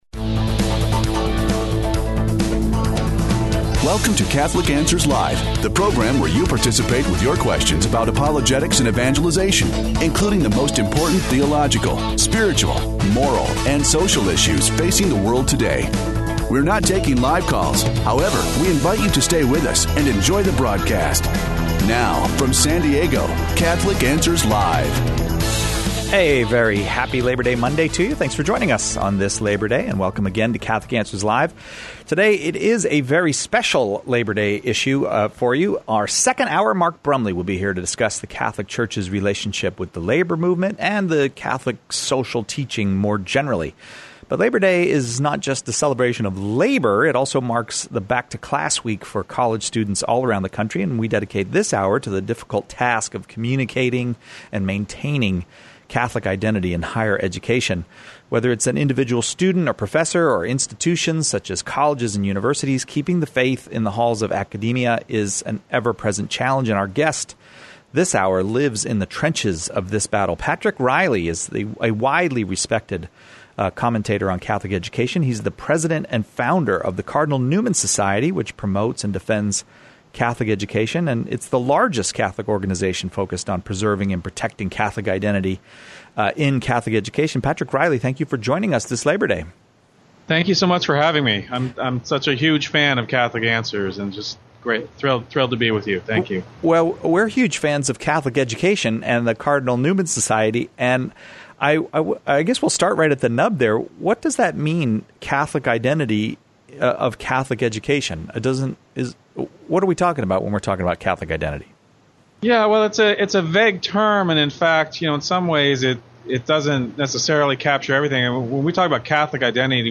Catholic Higher Education (Pre-recorded)